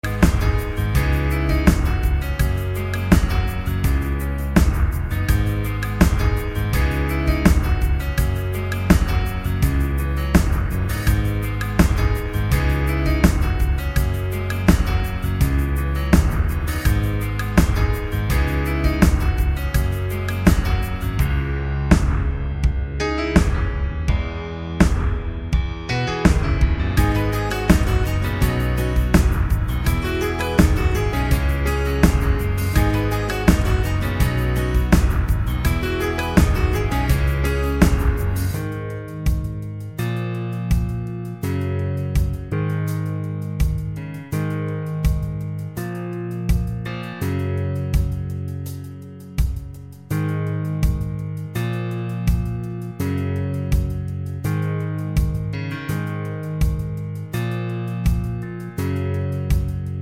Minus Main Guitar Pop (1990s) 5:06 Buy £1.50